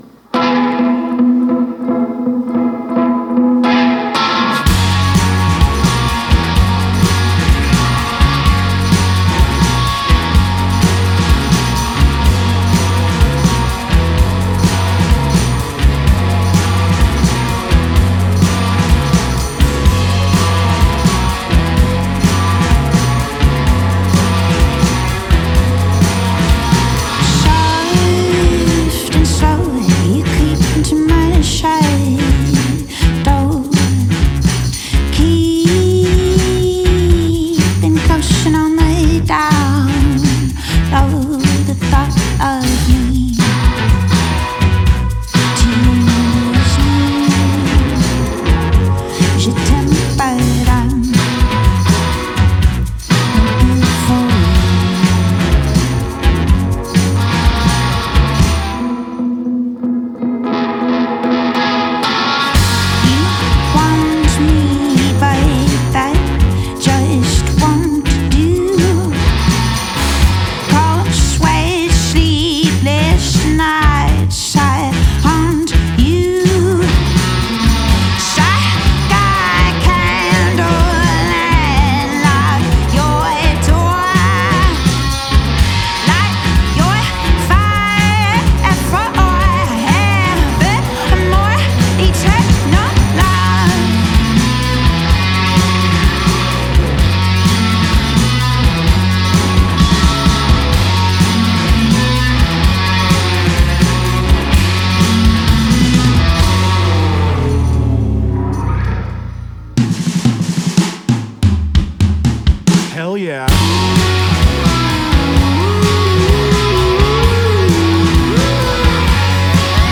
Diving into some Americana tonight